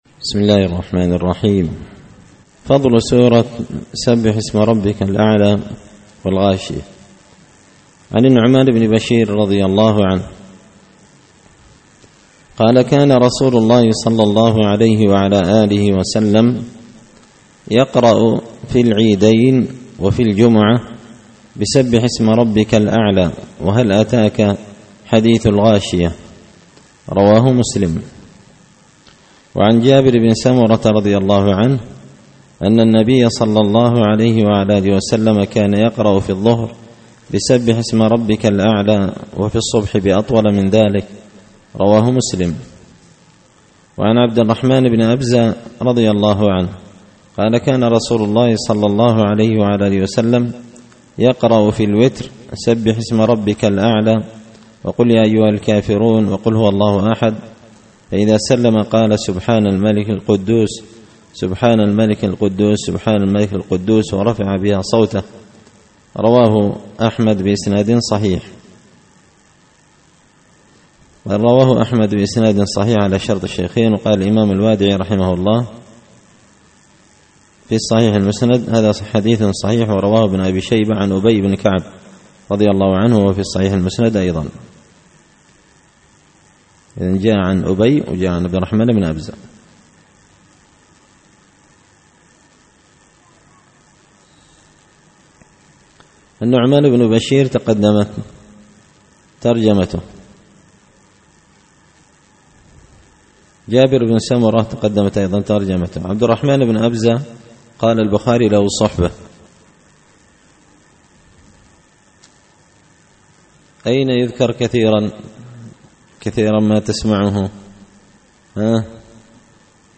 الأحاديث الحسان فيما صح من فضائل سور القرآن ـ الدرس الخامس والأربعون